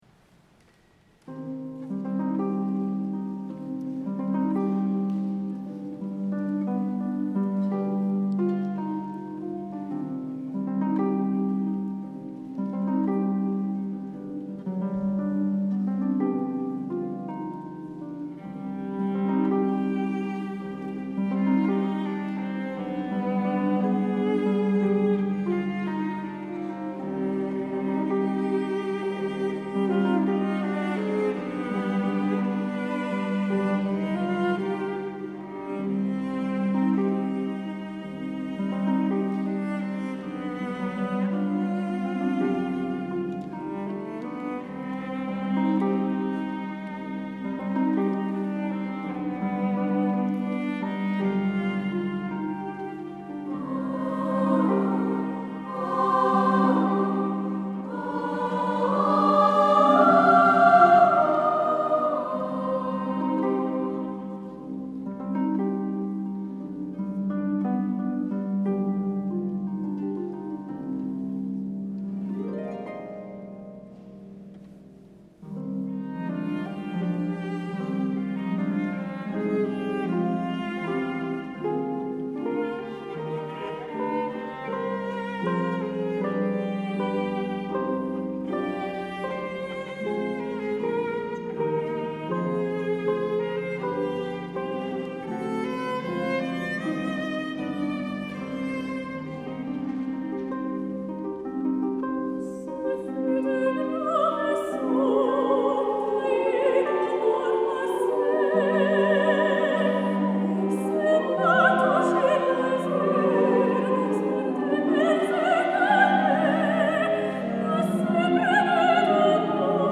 oeuvre pour soliste, choeur de voix de femmes, alto, violoncelle et harpe